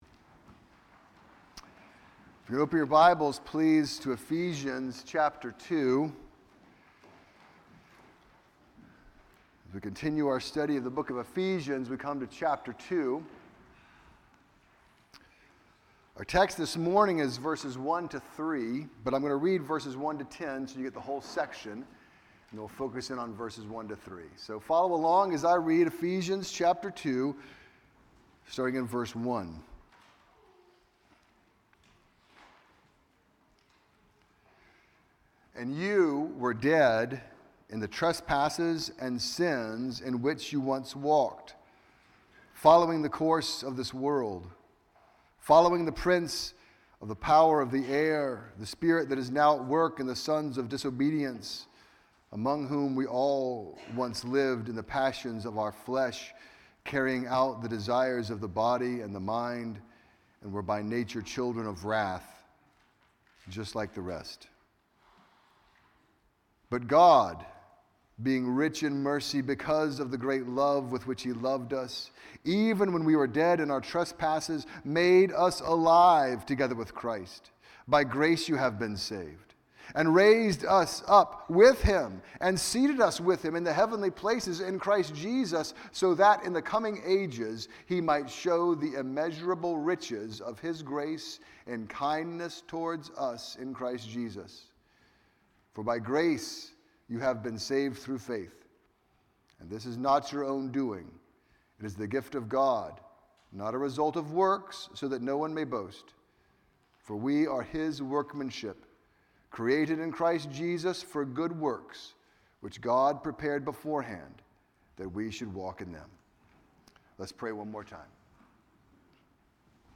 A message from the series "Exposition of Ephesians."